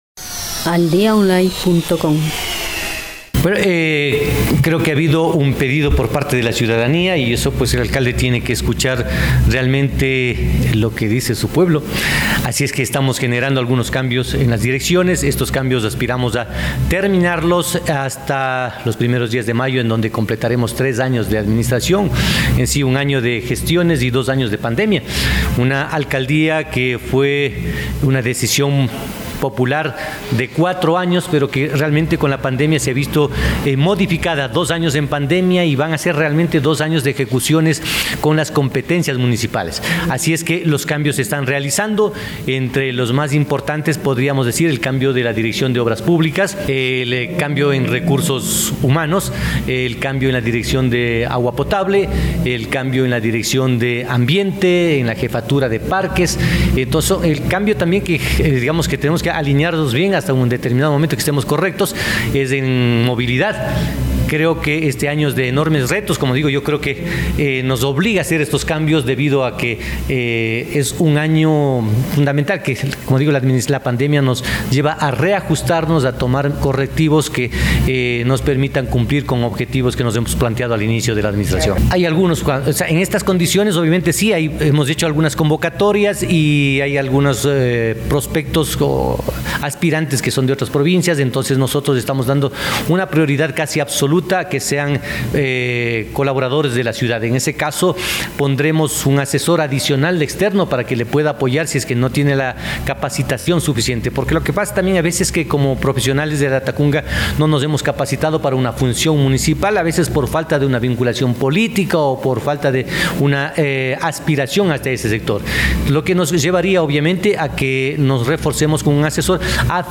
INTERVIENE: Byron Cárdenas, Alcalde de Latacunga